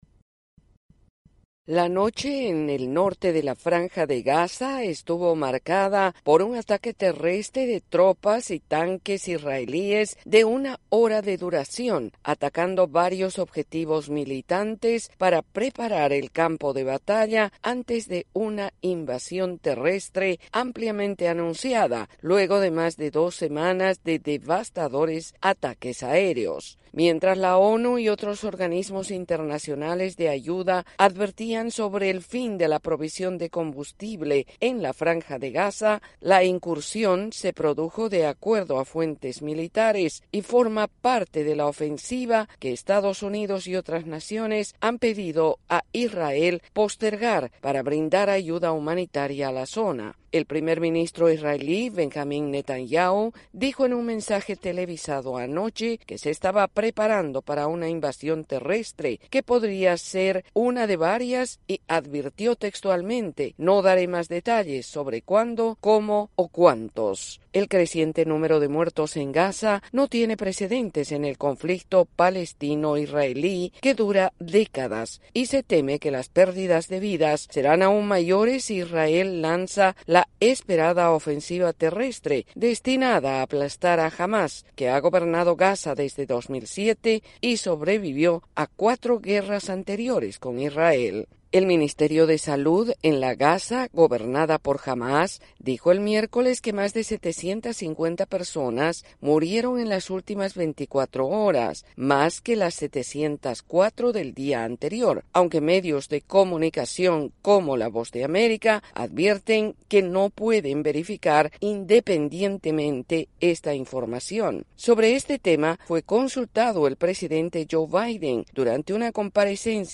El ejército israelí lanzó un ataque de una hora contra posiciones que califica como “objetivos militares” en el norte Gaza mientras la crisis humanitaria crece. El reporte